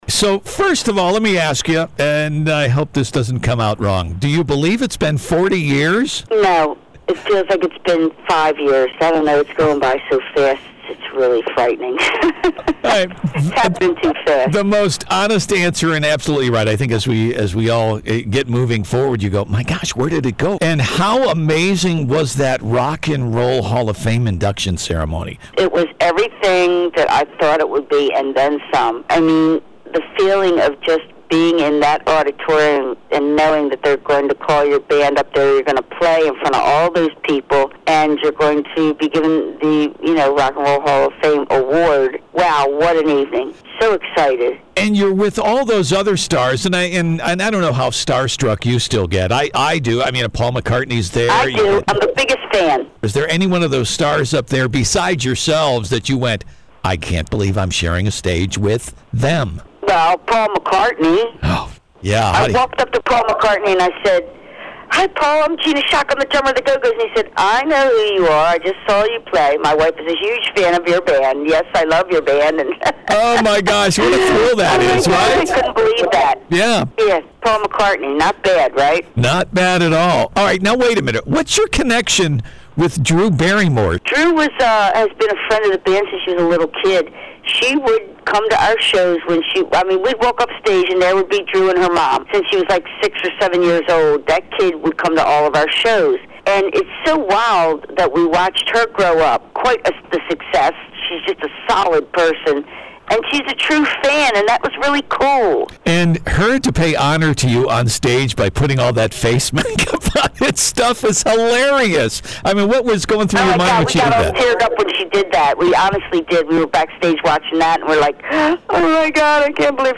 Gina Schock, Go-Go’s Drummer Talks Hall of Fame Induction